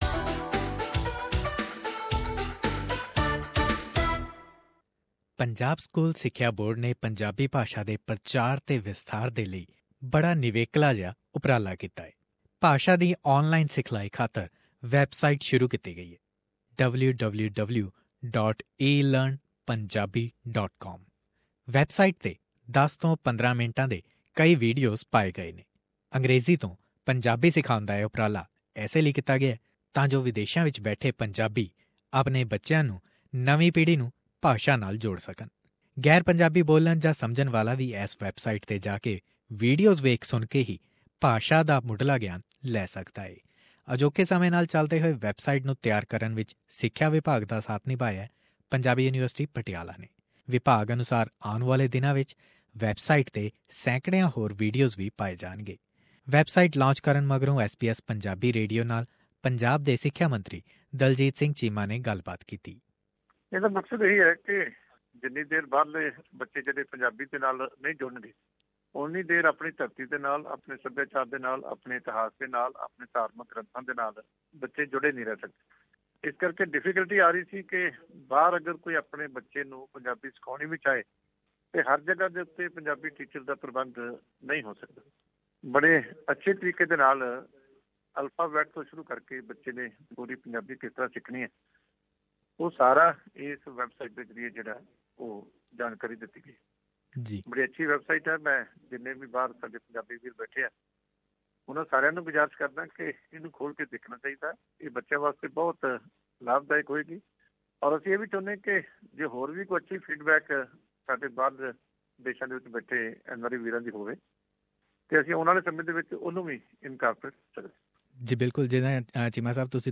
Punjab School Education Board has launched a website for learning Punjabi language and to spread it across the world. Punjab's education minister Daljit Singh Cheema said how people all over the world can benefit from it.